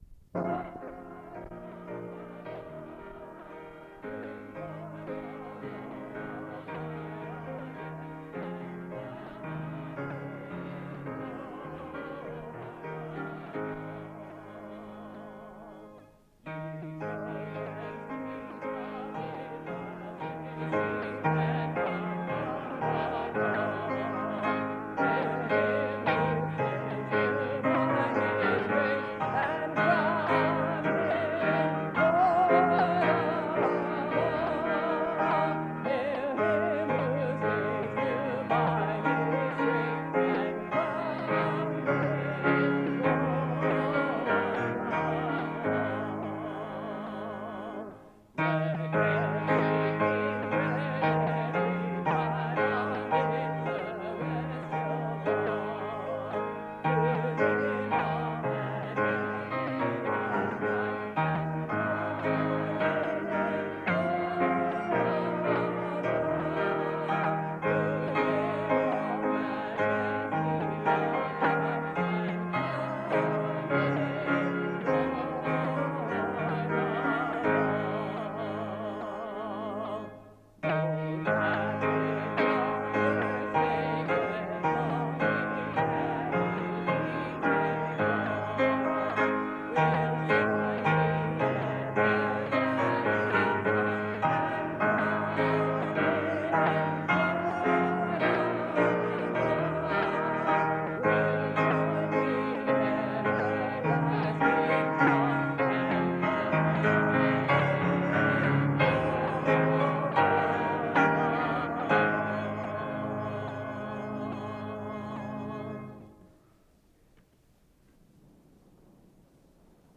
SEBTS Chapel - Donald A. Carson February 8, 2000
SEBTS Chapel and Special Event Recordings